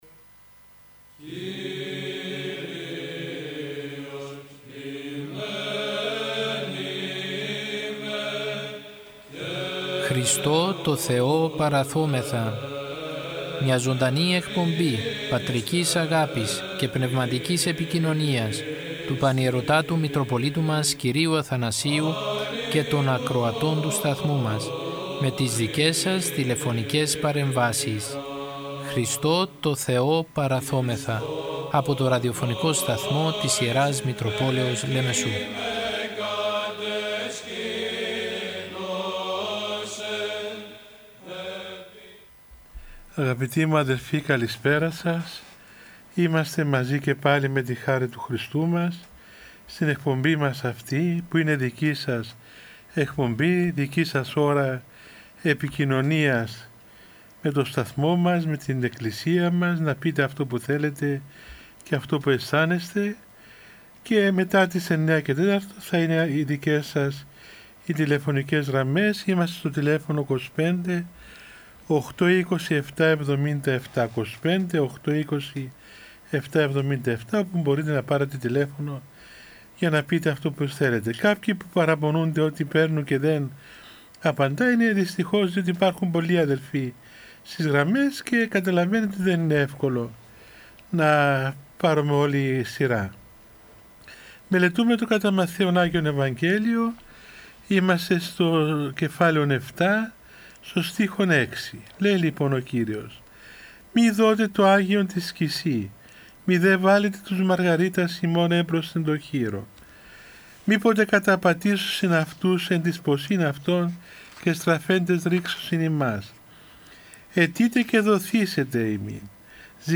Ο Πανιερώτατος Μητροπολίτης Λεμεσού κ. Αθανάσιος μέσω του ραδιοφωνικού σταθμού της Μητροπόλεως του καθημερινά επικοινωνεί με το ποίμνιο με τηλεφωνικές συνδέσεις και απευθύνει παρηγορητικό λόγο για την δοκιμασία που περνάει ο Ορθόδοξος λαός για την πανδημία με κλειστές εκκλησίες και απαγορεύσεις.